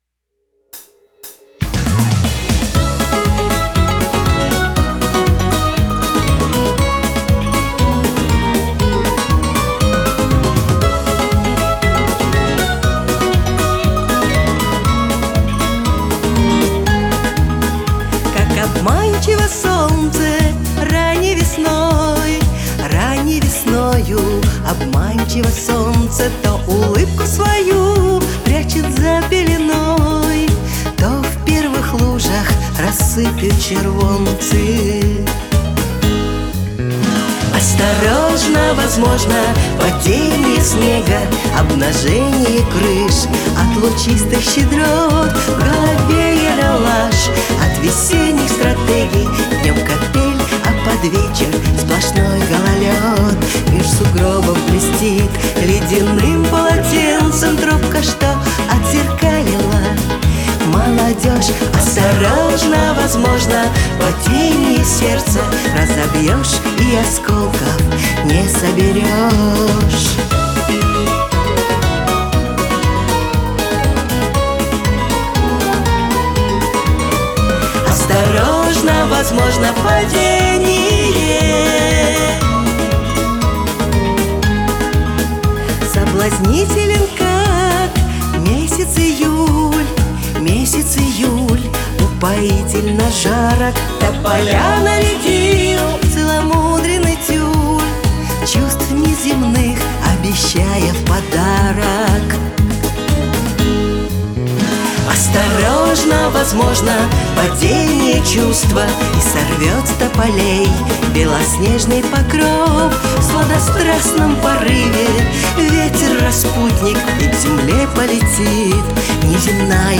гитары